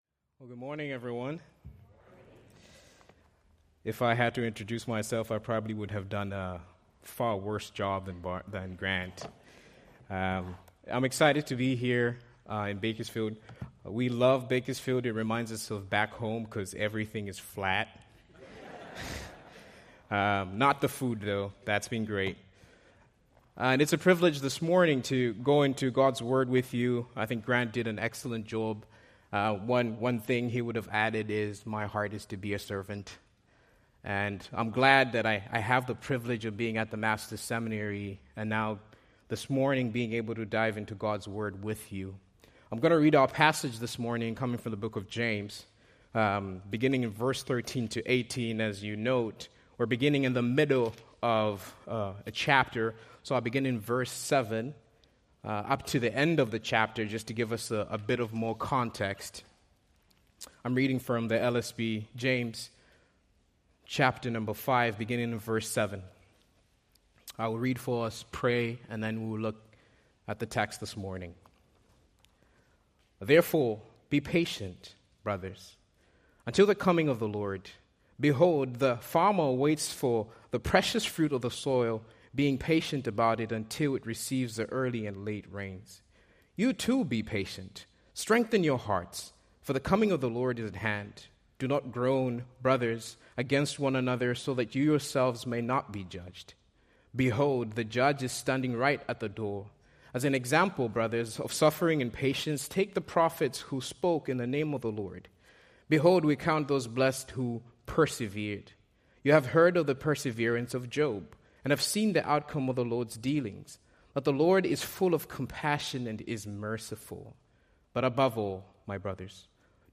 Preached January 5, 2025 from James 5:13-18